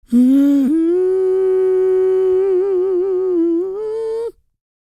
E-CROON P319.wav